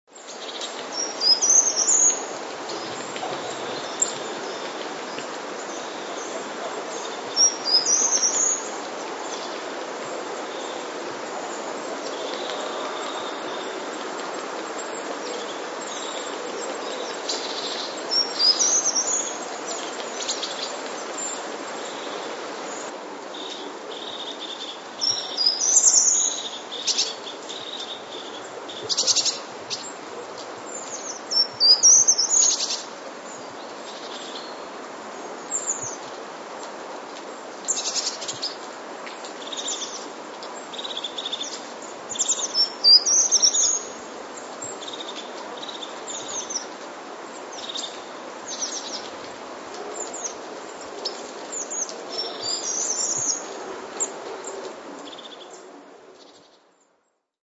Pełzacz ogrodowy - Certhia brachydactyla
śpiewającego pełzacza jeszcze wcześniej, mianowicie ósmego stycznia.
Piosenki pełzacza ogrodowego są proste, krótkie i rozdzielone wyraźnymi przerwami. W odróżnieniu od pełzacza leśnego mają charakter wznoszący, a ostatnia sylaba jest najwyższa i najdłuższa. Poza tym, układ sylab w piosence pełzacza ogrodowego nadaje jej charakterystyczny, trochę taneczny rytm.